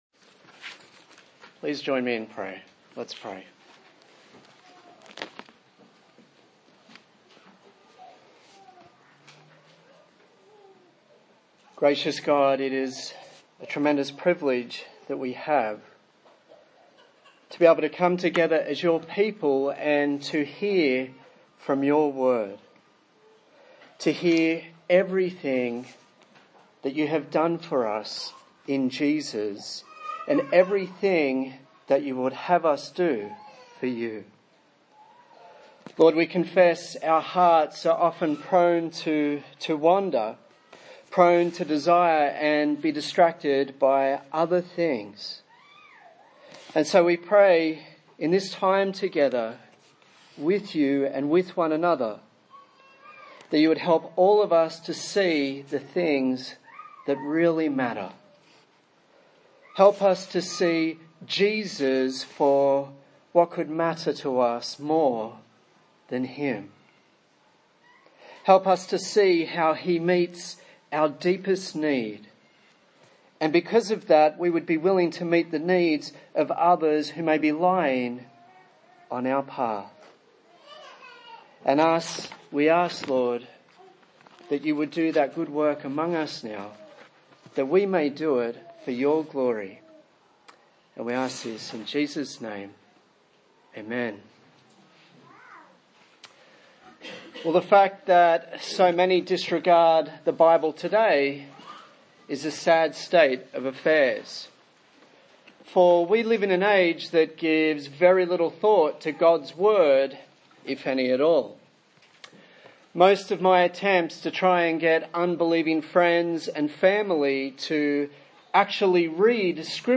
Luke Passage: Luke 10:25-37 Service Type: Sunday Morning